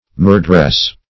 Meaning of murdress. murdress synonyms, pronunciation, spelling and more from Free Dictionary.
Search Result for " murdress" : The Collaborative International Dictionary of English v.0.48: Murdress \Mur"dress\, n. A battlement in ancient fortifications with interstices for firing through.